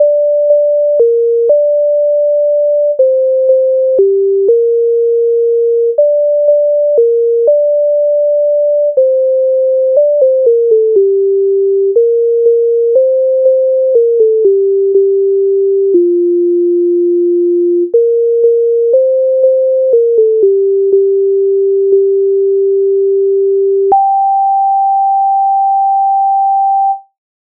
Ой ходить сон Українська народна пісня
Ukrainska_narodna_pisnia_Oj_khodyt_son.mp3